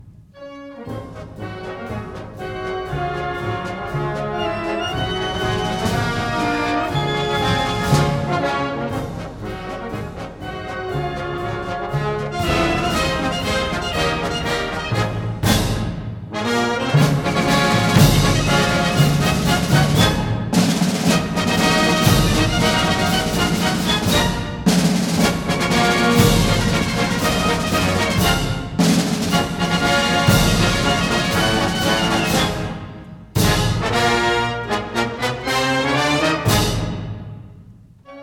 1961 stereo recording